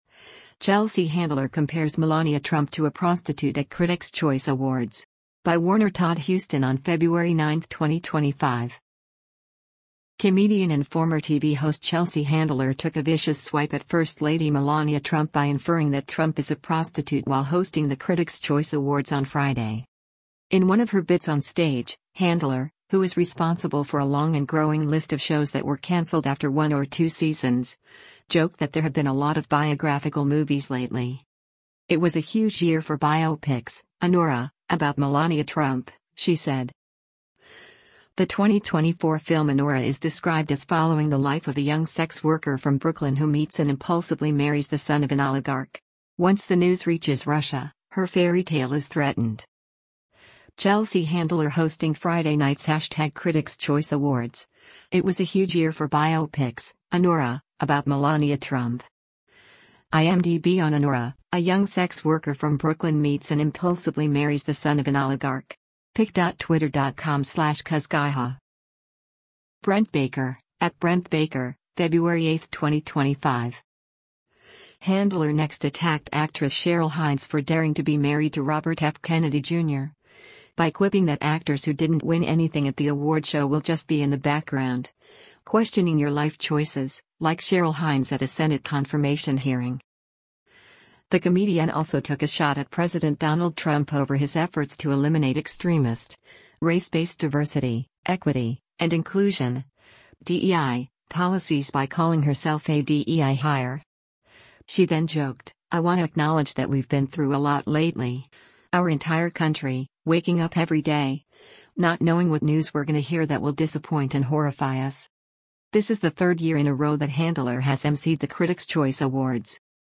SANTA MONICA, CALIFORNIA - FEBRUARY 07: Host Chelsea Handler speaks onstage during the 30t